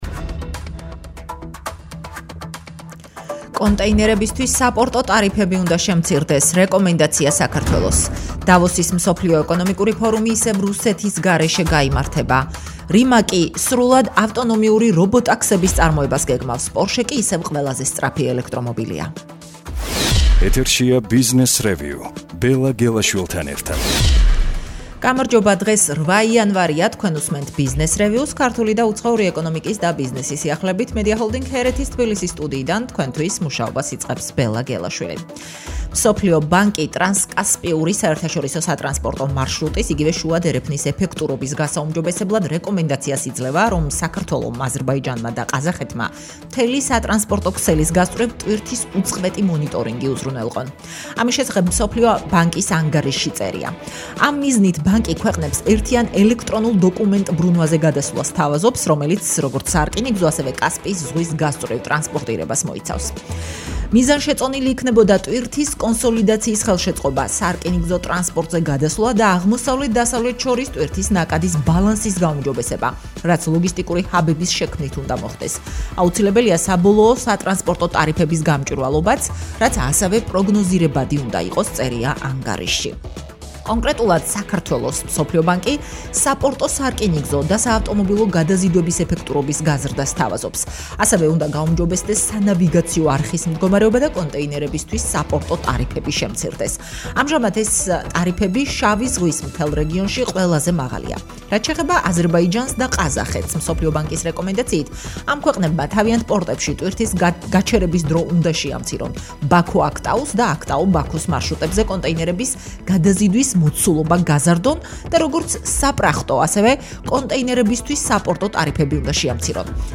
რადიოგადაცემა